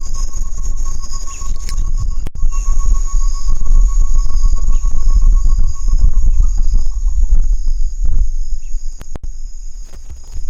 Tovaca-campainha (Chamaeza campanisona)
Nome em Inglês: Short-tailed Antthrush
Localidade ou área protegida: Ruta 15 Biosfera Yaboti
Condição: Selvagem
Certeza: Gravado Vocal
C.-campanisona.mp3